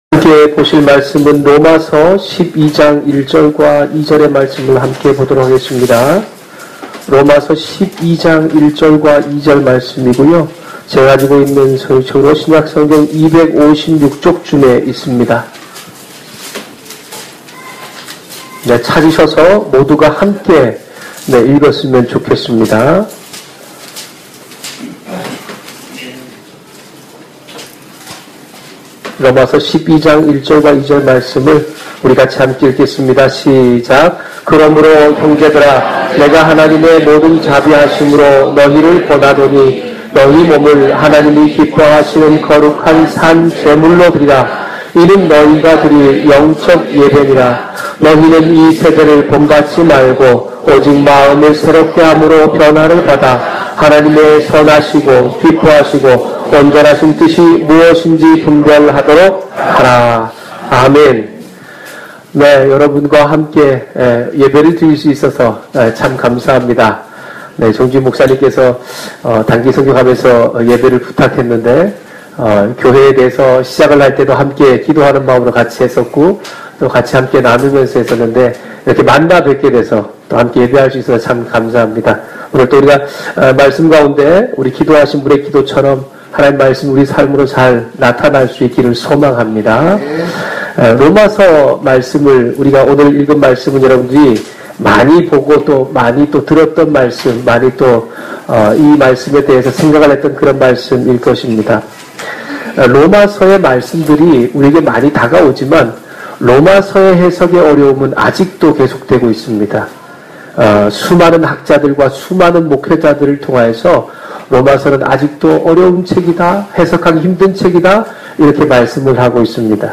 설교 - 2015년09월13일 레1장1~17절 ( 제목:하나님이 원하시는 예배 ) ( 새가족 교육 1번)